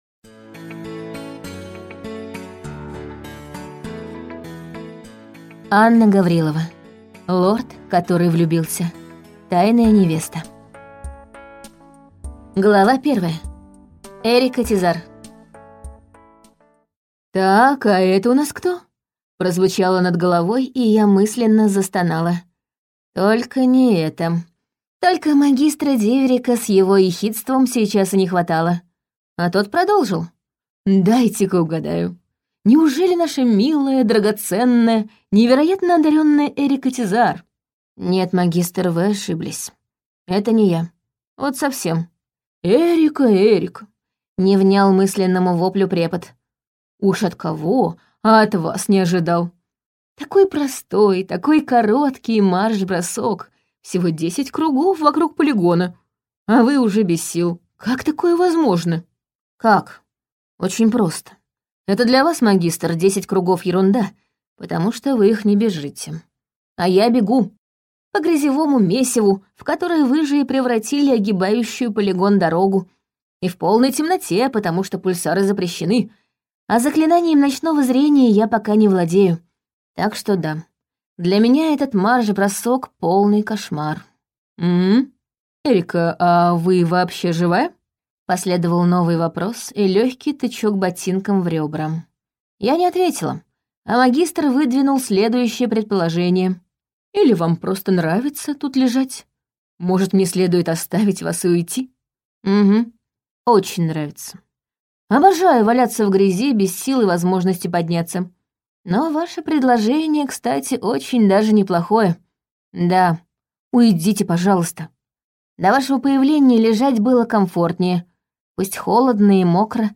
Аудиокнига Лорд, который влюбился.